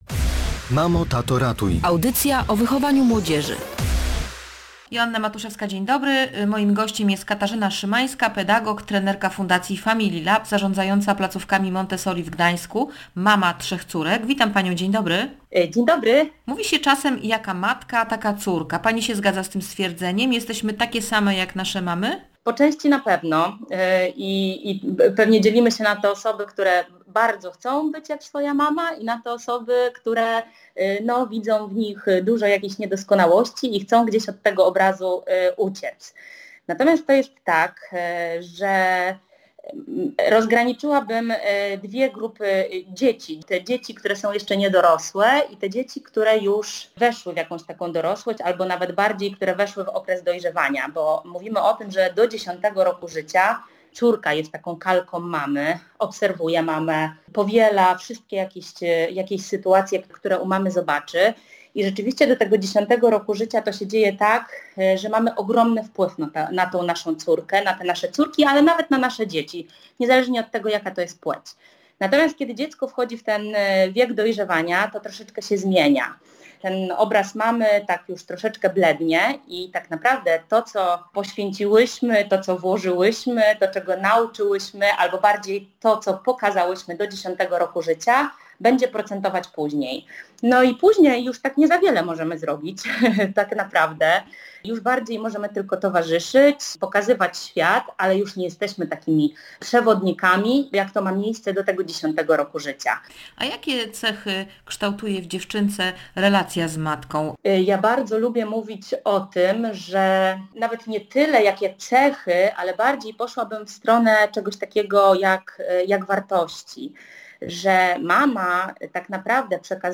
Mama i córka. Rozmowa o relacjach z pedagog, która jest mamą trzech dziewczynek